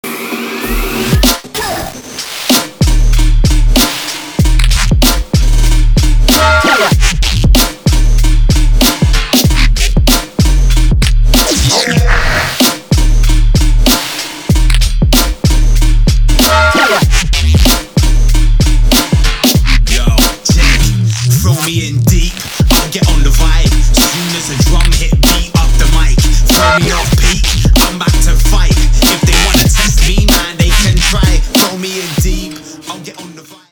• Качество: 320, Stereo
Trap